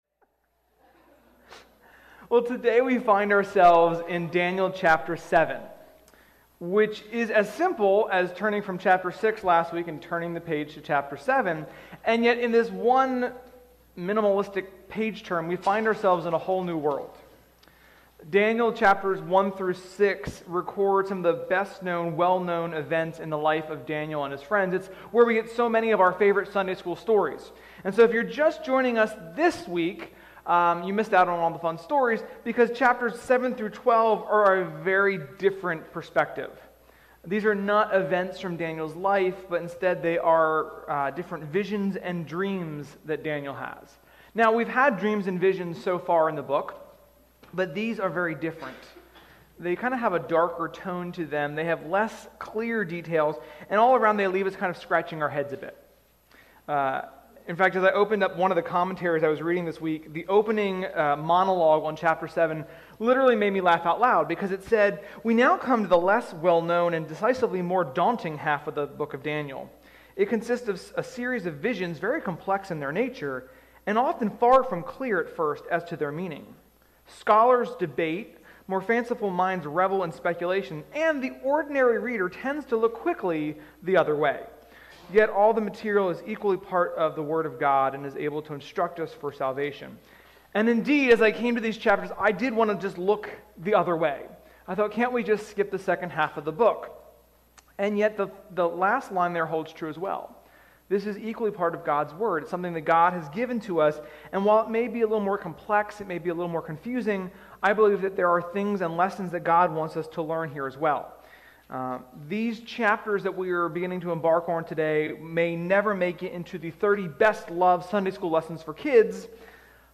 Sermon-7.25.21.mp3